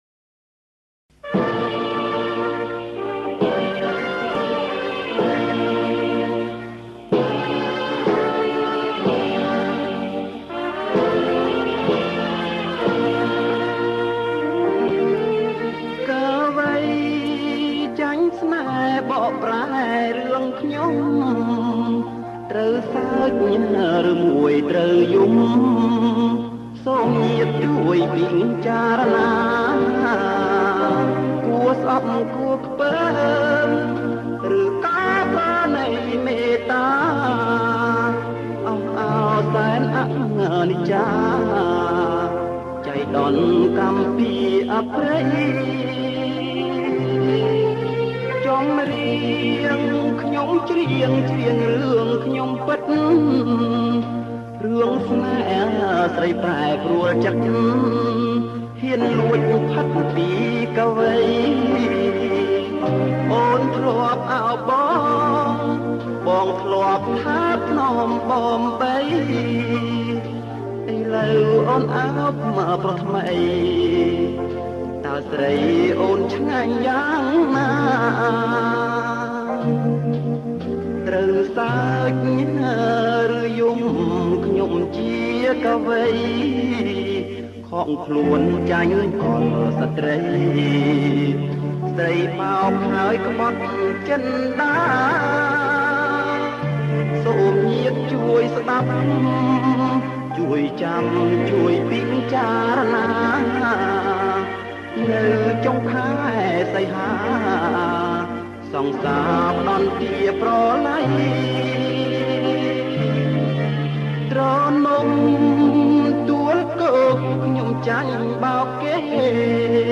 • ប្រគំជាចង្វាក់ Slow